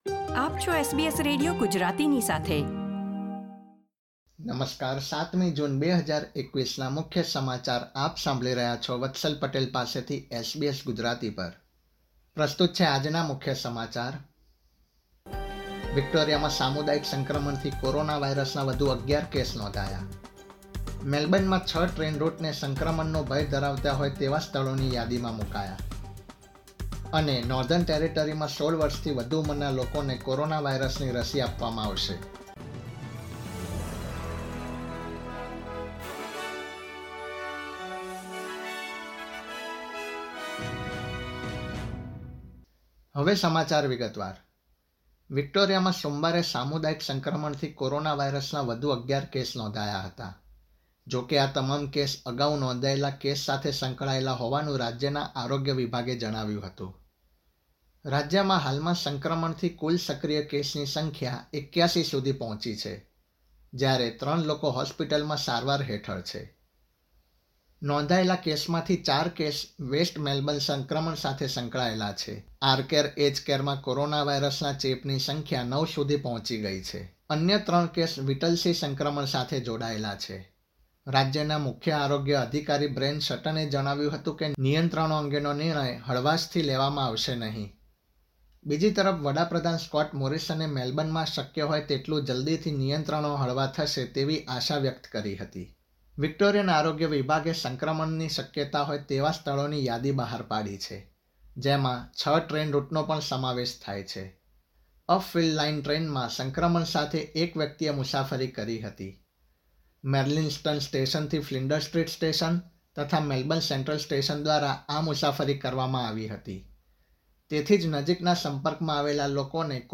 SBS Gujarati News Bulletin 7 June 2021
gujarati_0706_newsbulletin.mp3